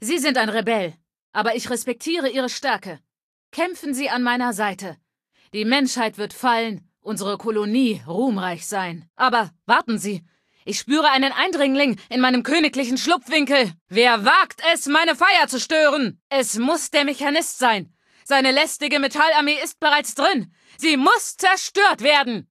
Datei:Femaleadult01default ms02 ms02superheroexplain3 0003c8ca.ogg
Fallout 3: Audiodialoge